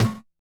snare03.wav